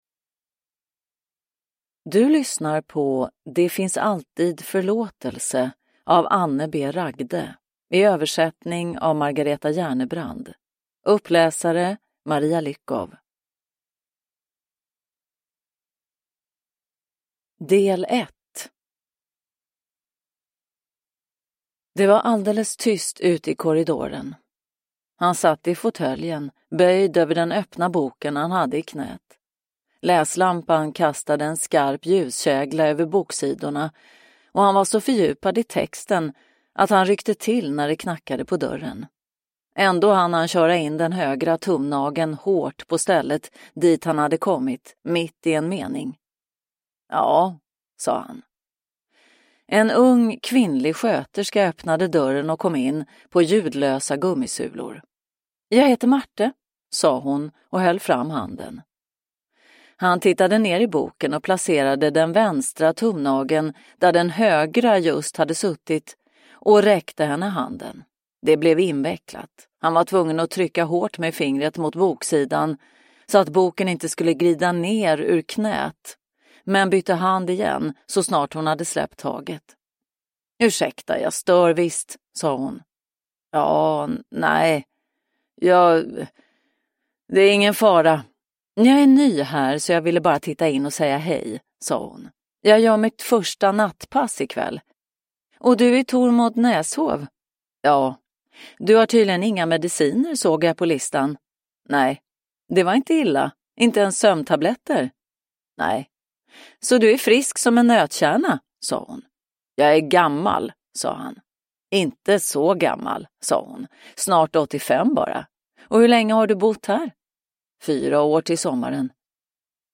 Det finns alltid förlåtelse – Ljudbok – Laddas ner